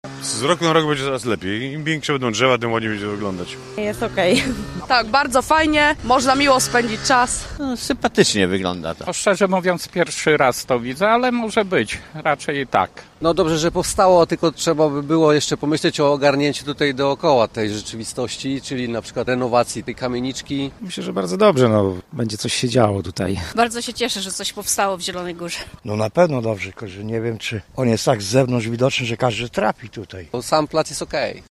Ukończono Plac Teatralny [SONDA]